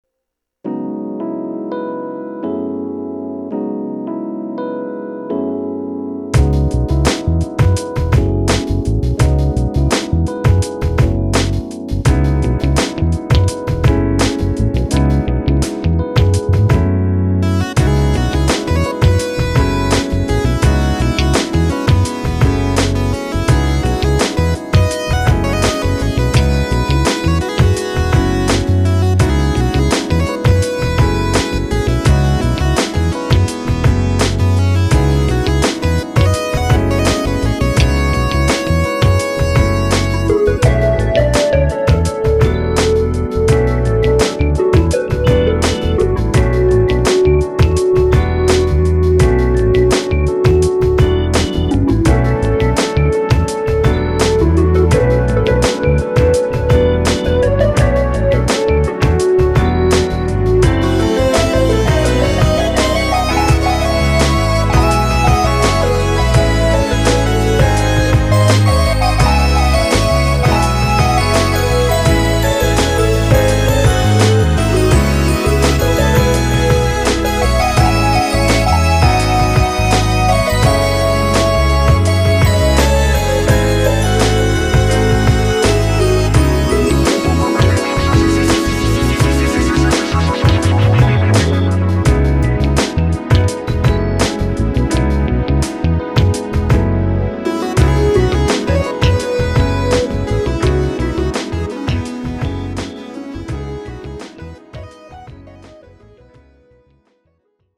BPM84
MP3 QualityMusic Cut